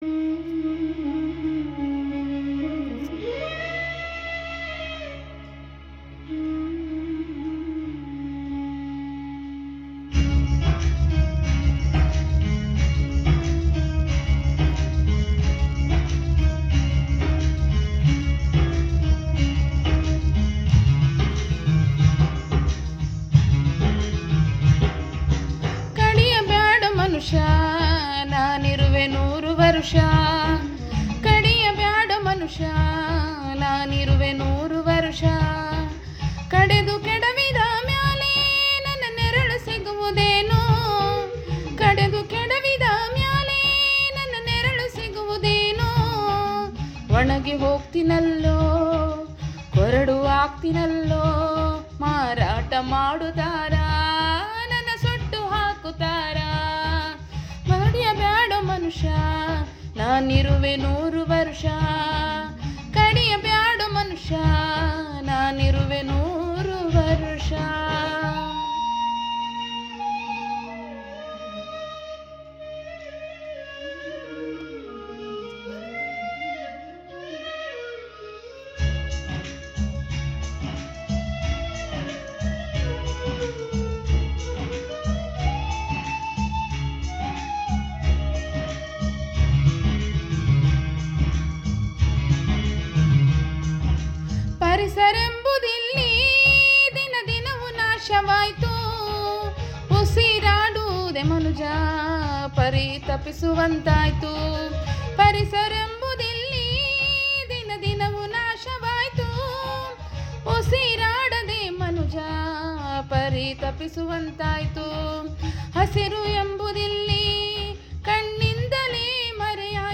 [ಪರಿಸರ ಜಾಗೃತಿ ಕವನ ( ಗೀತೆ)]